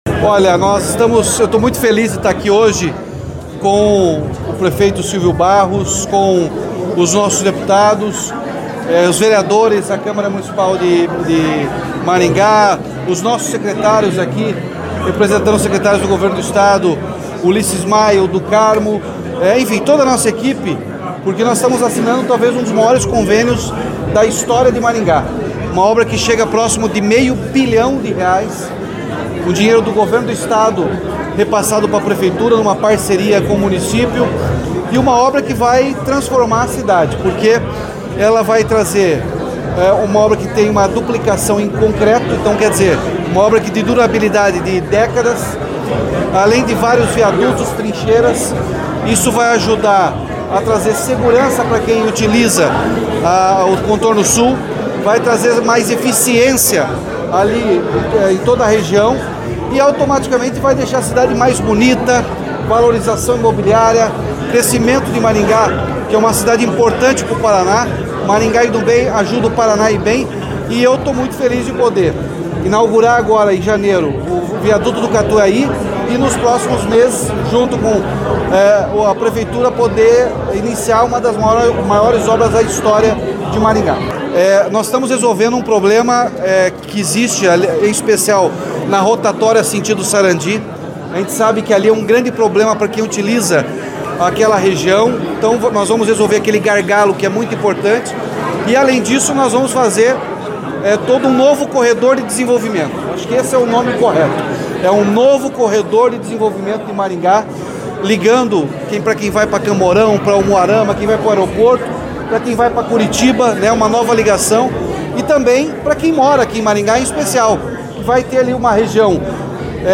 Sonora do governador Ratinho Junior sobre a formalização do convênio para a nova etapa da duplicação do Contorno Sul de Maringá